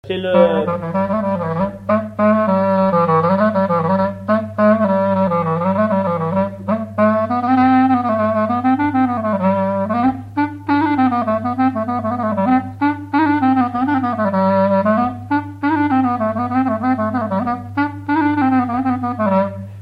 instrumental
danse : quadrille : pas d'été
Pièce musicale inédite